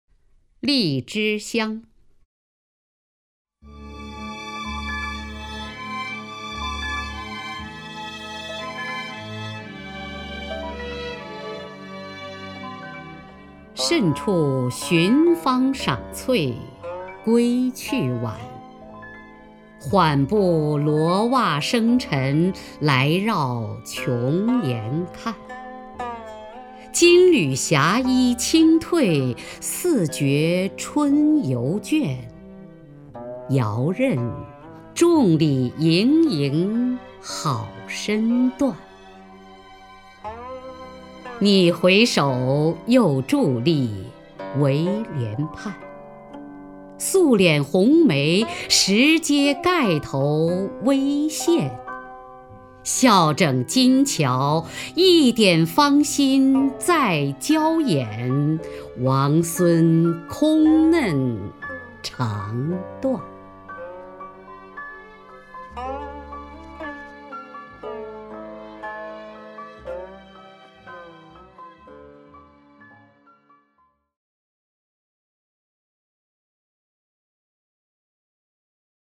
首页 视听 名家朗诵欣赏 雅坤
雅坤朗诵：《荔枝香·甚处寻芳赏翠》(（北宋）柳永)